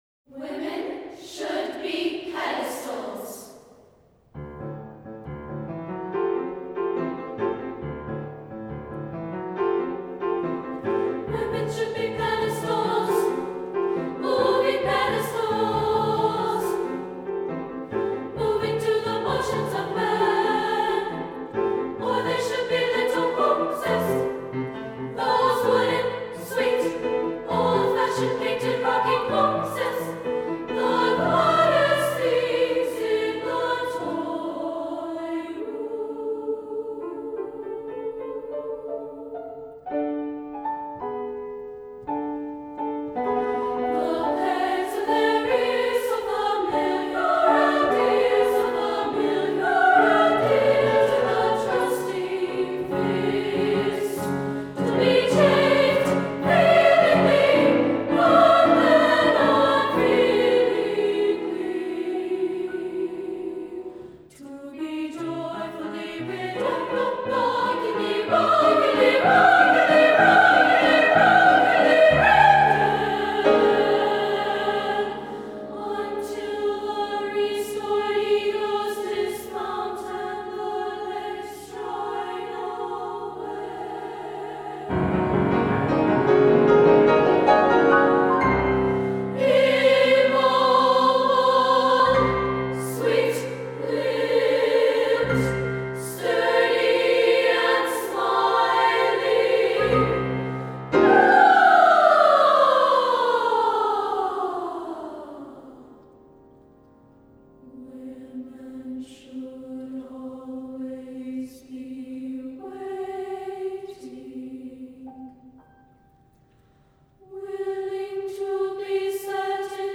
for SSAA Chorus and Piano (1993)